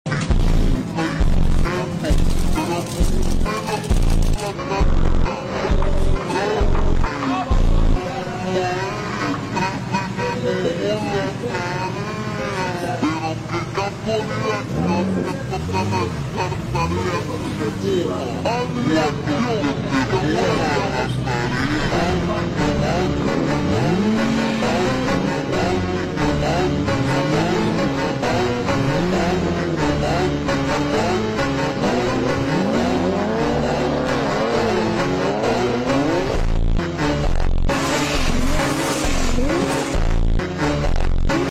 Supra Mk4 vs Skyline GTR sound effects free download